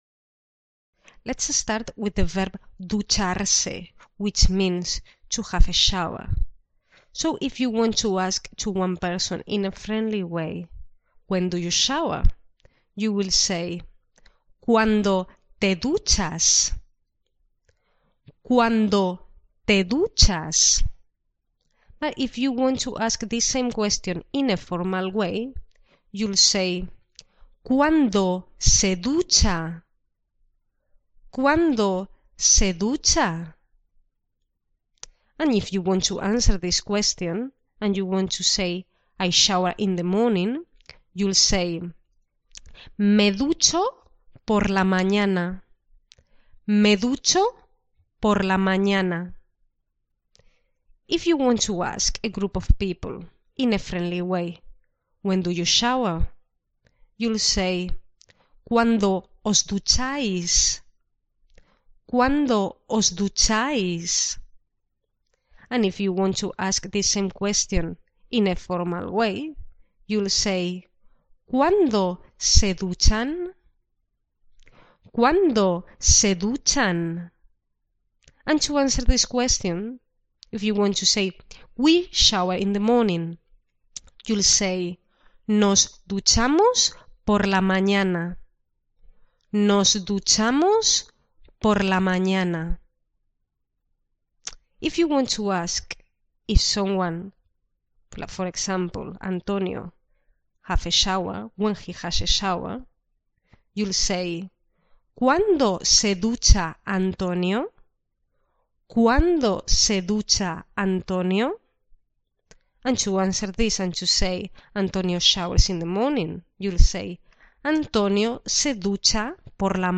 Pronunciation
12.-Video-Lessons-Reflexive-Verbs-Part-2.mp3